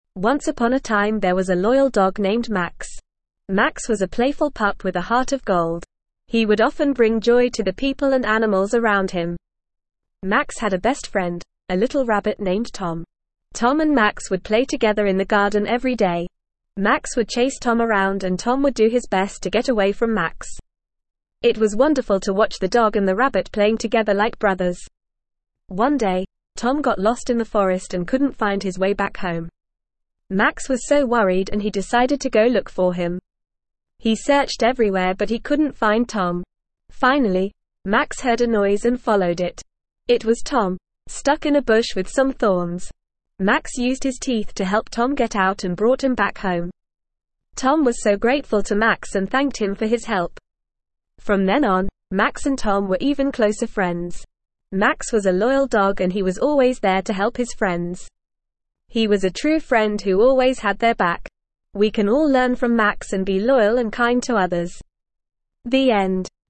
Normal
ESL-Short-Stories-for-Kids-NORMAL-reading-The-Loyal-Dog.mp3